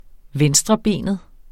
Udtale [ -ˌbeˀnəð ]